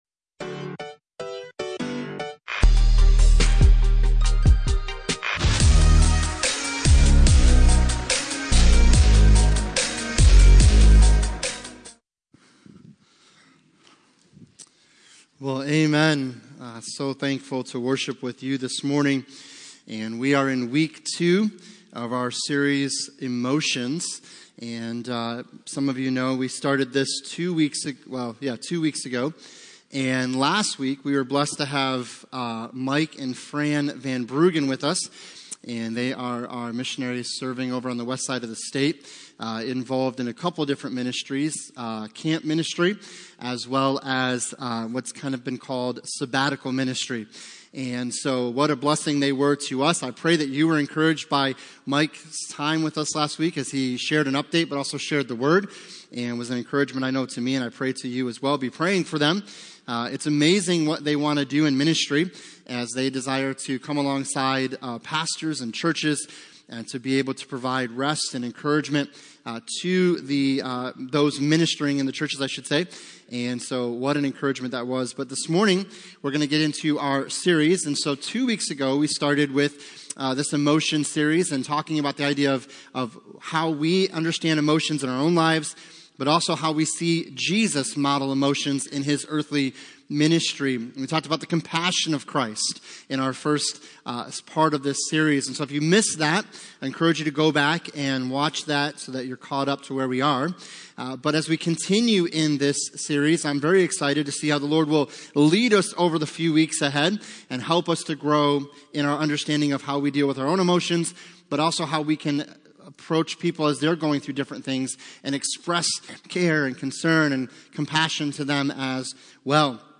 Passage: Luke 9:57-58 Service Type: Sunday Morning